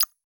Modern Click 2.wav